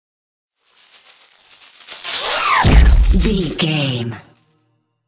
Whoosh to hit sci fi disappear debris
Sound Effects
Atonal
dark
futuristic
intense
tension
woosh to hit